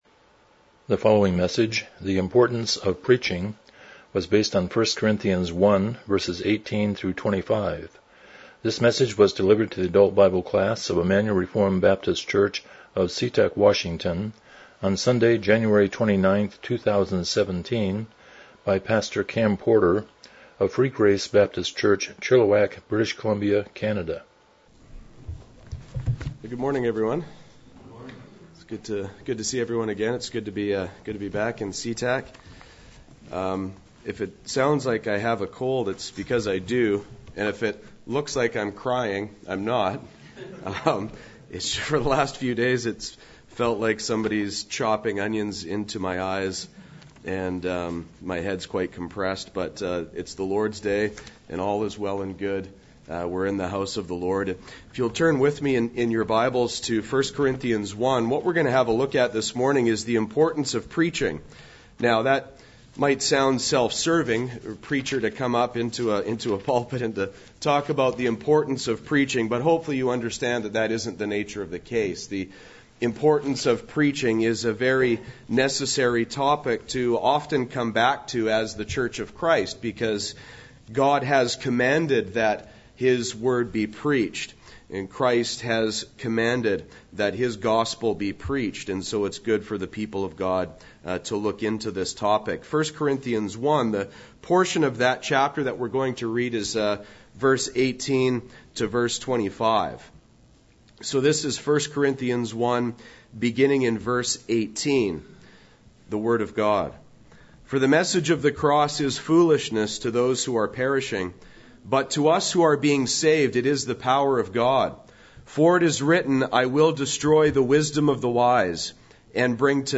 Miscellaneous Passage: 1 Corinthians 1:18-25 Service Type: Sunday School « Fear